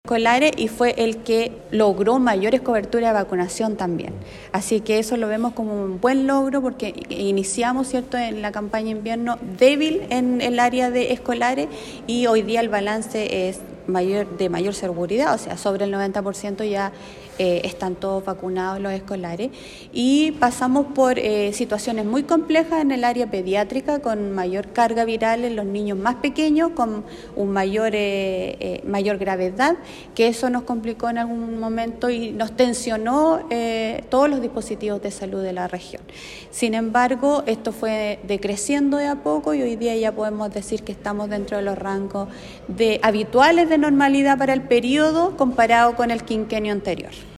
Esto responde de cierto punto a los buenos resultados que tuvo Osorno, frente a la Campaña de Invierno y la vacunación de escolares, que logró duplicar su cifra inicial, como lo explicó la Seremi de Salud de Los Lagos, Karin Solís.